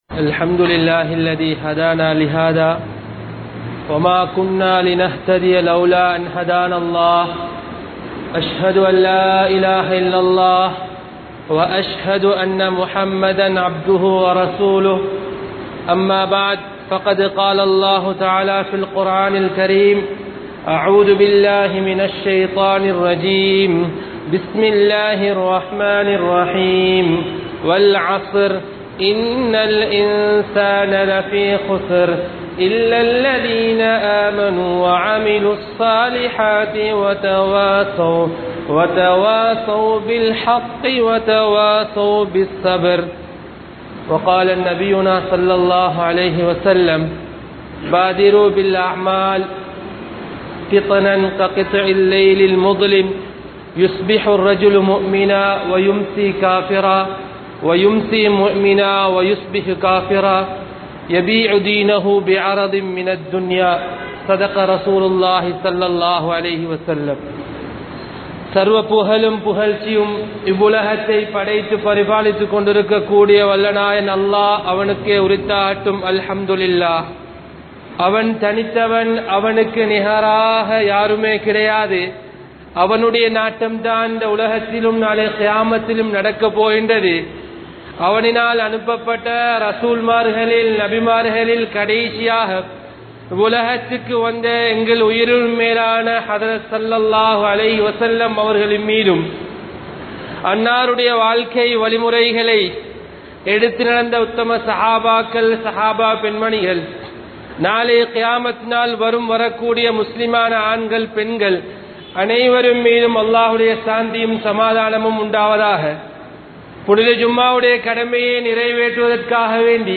Qiyamath Nerungi Vittaal (கியாமத் நெருங்கிவிட்டால்) | Audio Bayans | All Ceylon Muslim Youth Community | Addalaichenai